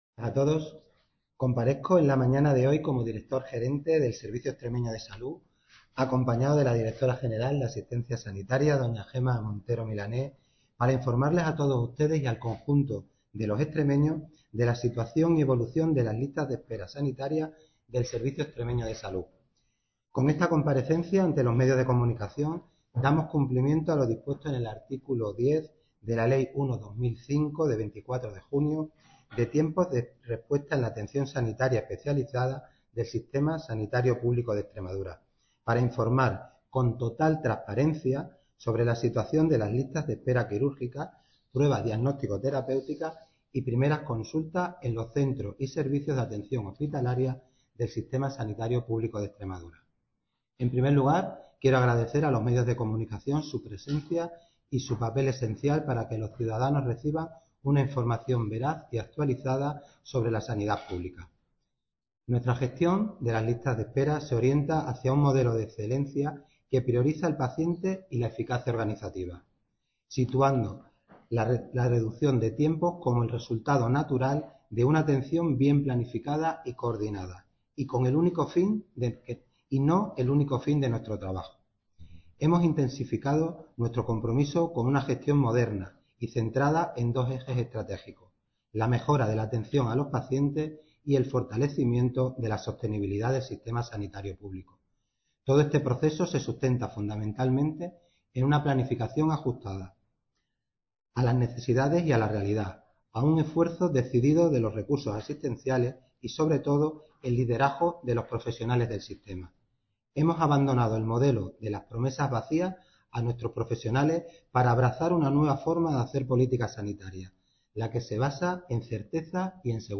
Así lo ha manifestado este viernes el director gerente del Servicio Extremeño de Salud (SES), Jesús Vilés, quien ha comparecido ante los medios de comunicación para informar sobre los tiempos de respuesta en la atención sanitaria especializada del Sistema Sanitario Público de Extremadura.